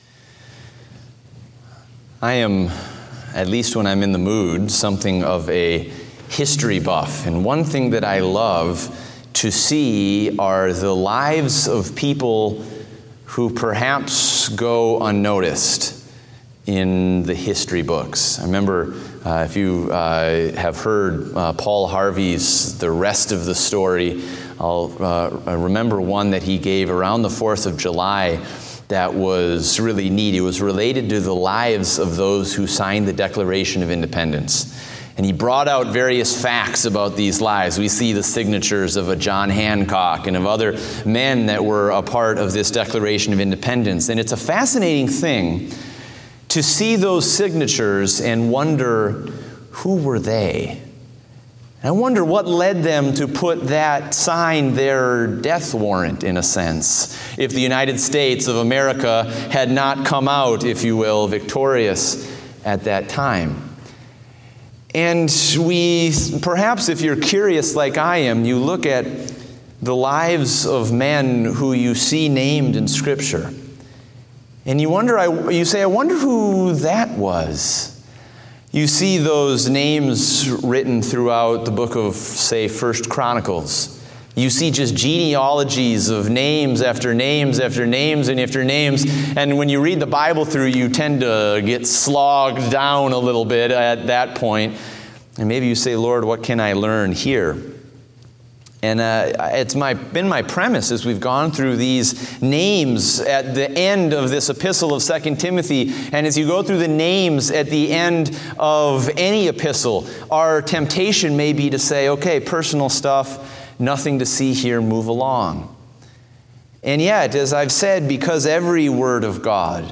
Date: August 14, 2016 (Evening Service)